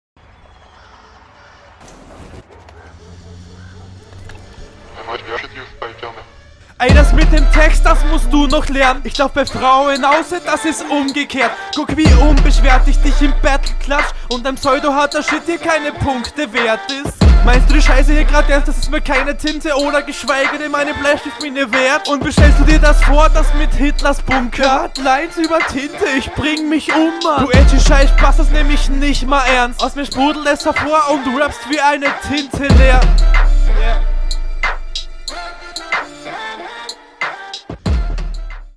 fand den einstieg schwächer als bei deinem gegner, bist auch leider nicht so energentisch. die …